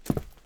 Footstep Concrete Walking 1_03.wav